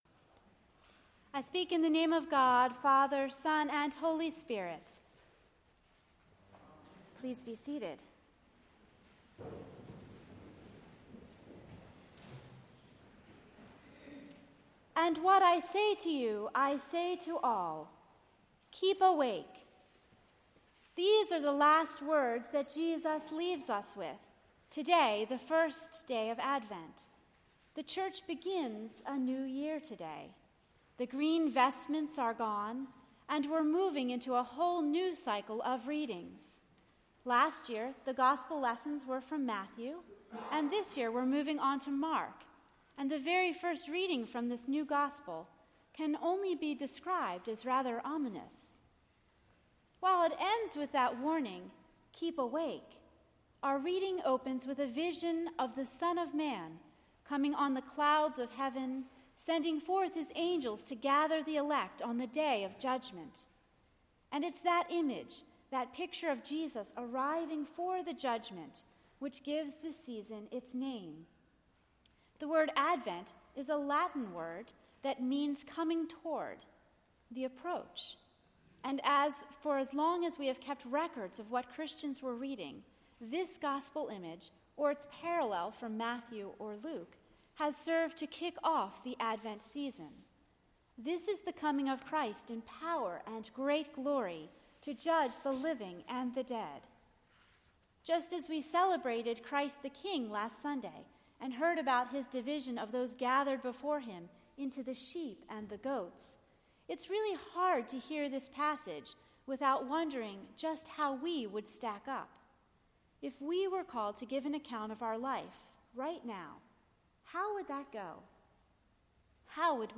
A Sermon for the First Sunday of Advent 2014